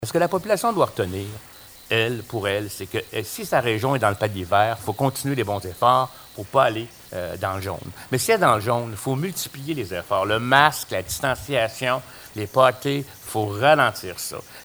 Le directeur national de la Santé publique, Horacio Arruda, explique qu’une région peut passer à un niveau plus strict de mesures de contrôle mais la situation est aussi réversible: